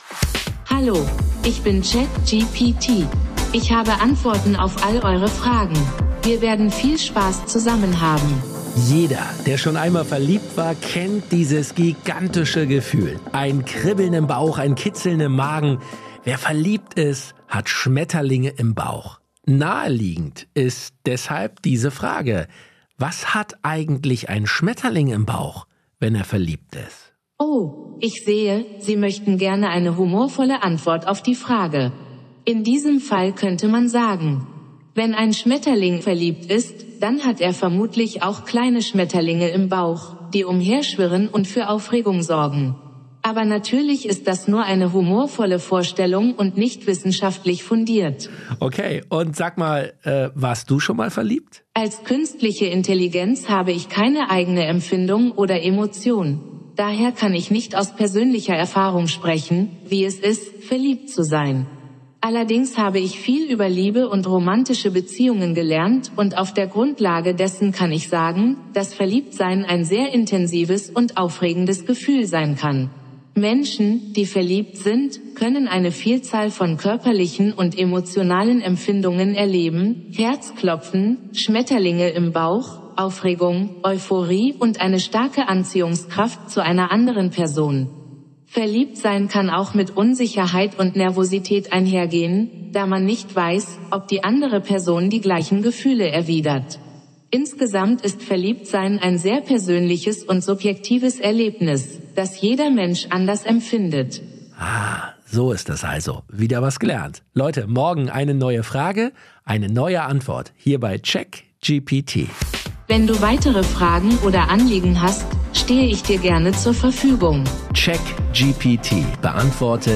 -- CheckGPT ist der weltweit erste tägliche Podcast mit der